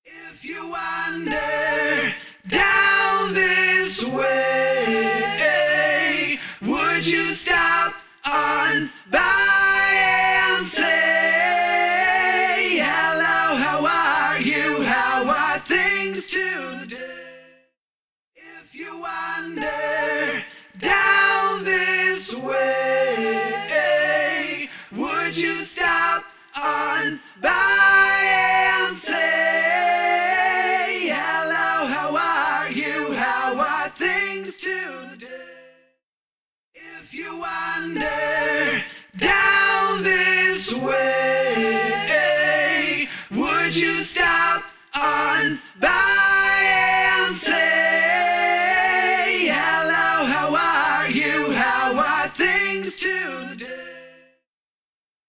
Invigorate | Vocals | Preset: Male Vox Bus Pusher
Invigorate-Male-Vox-Male-Vox-Bus-Pusher-CB.mp3